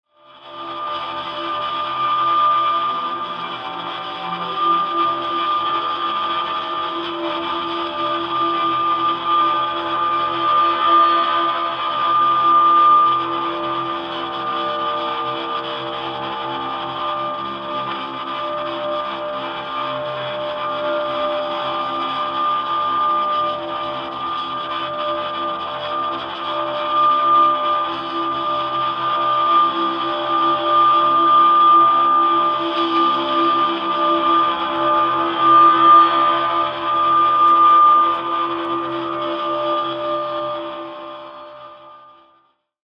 soundtrack from the film